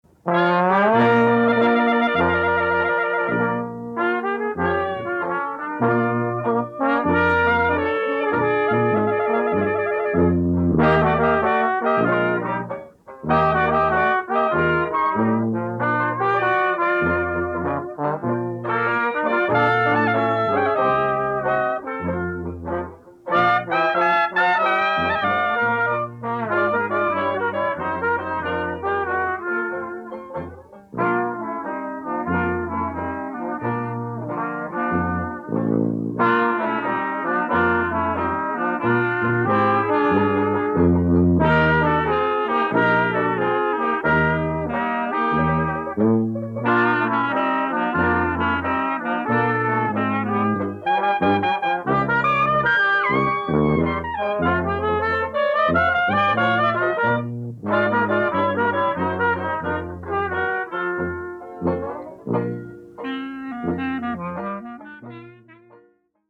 Red-Hot Ragtime Piano
“Tailgate” Trombone